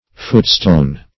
Footstone \Foot"stone`\ (?; 110), n.